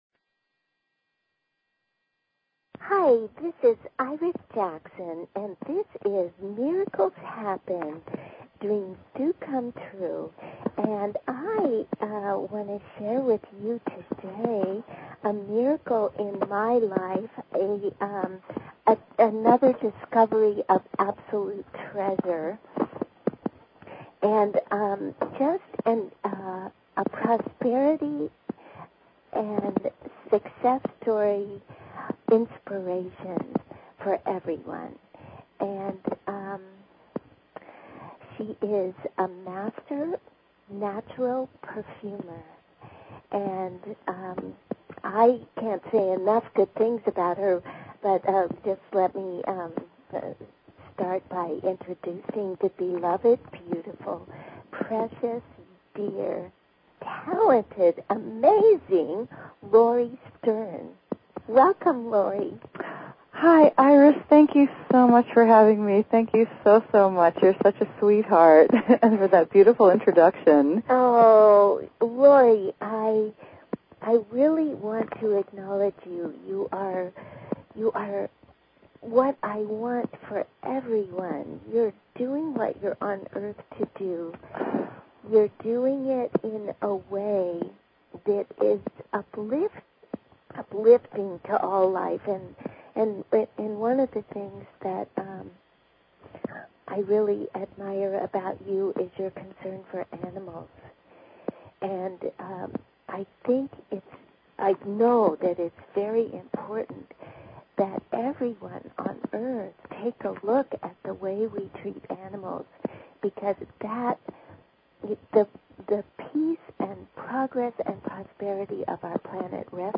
Talk Show Episode, Audio Podcast, Miracles_Happen and Courtesy of BBS Radio on , show guests , about , categorized as
Natural Perfumer Miracles Happen Please consider subscribing to this talk show.